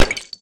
Folder: shotgun
explode2.ogg